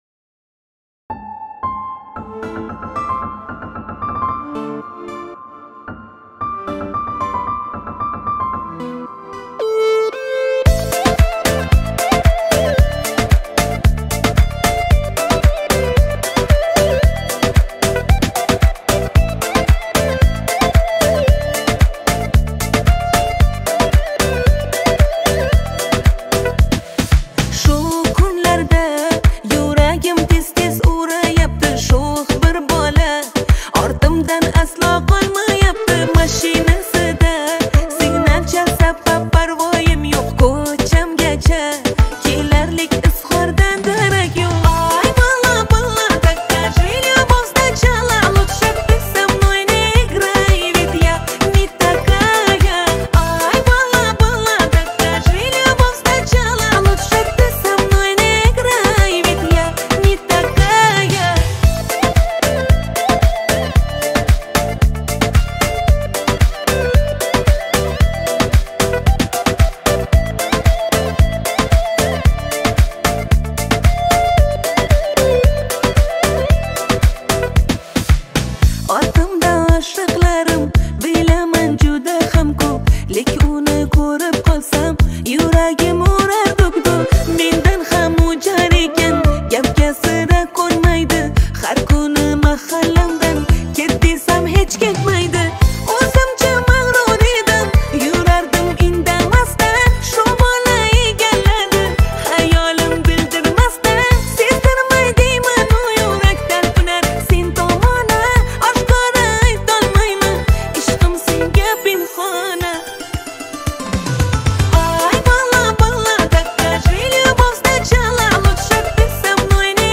• Категория: Узбекская музыка